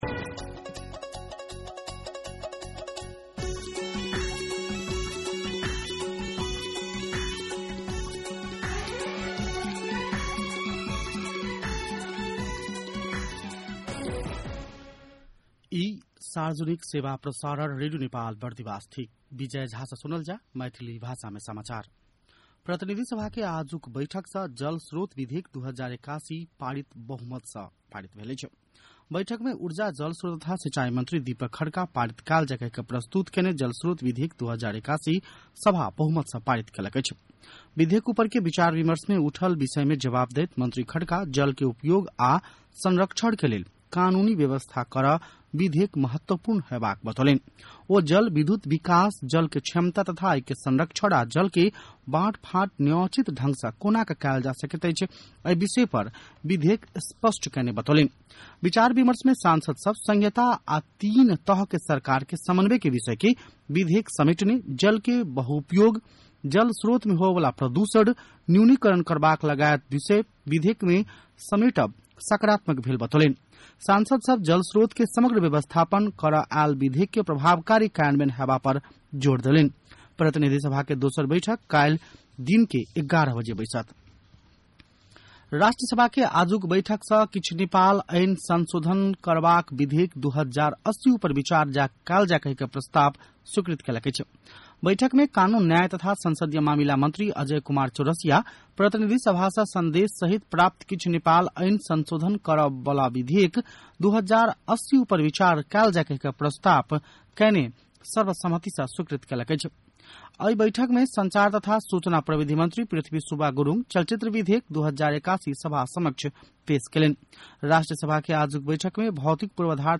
मैथिली भाषामा समाचार : ५ जेठ , २०८२
6.-pm-maithali-news-1-1.mp3